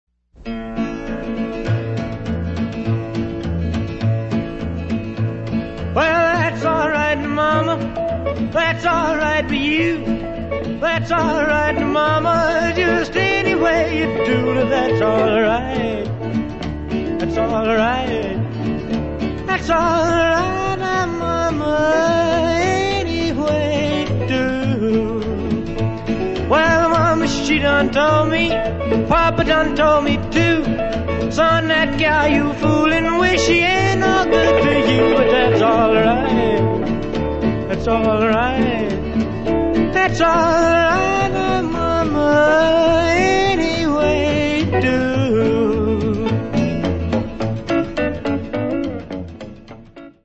gitarist
bassist
een heftig nummer